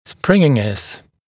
[‘sprɪŋɪnɪs][‘спрининис]пружинистость, упругость